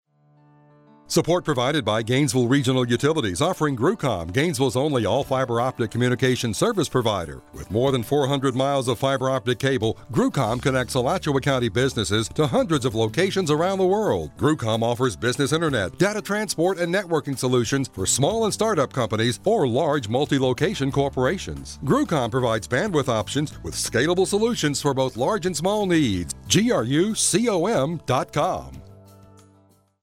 Length Radio Spot